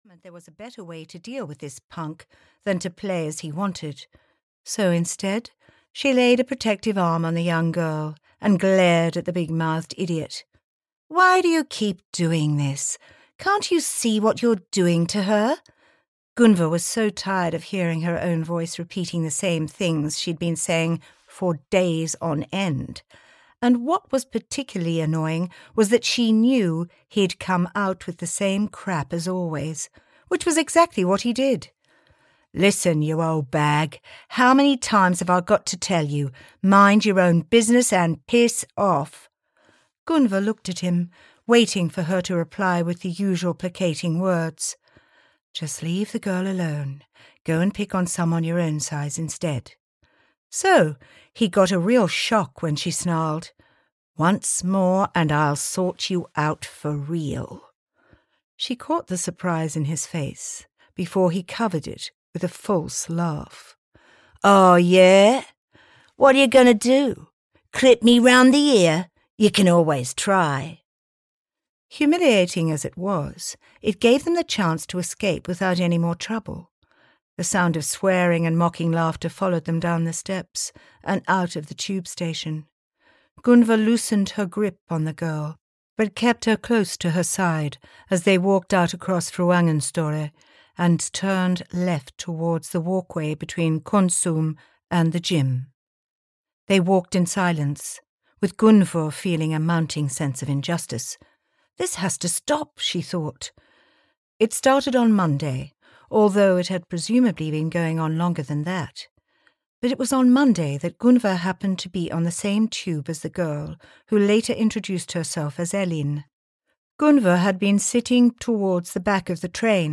Audio knihaLooking for Alice: a gritty, fast-paced Nordic Noir thriller (EN)
Ukázka z knihy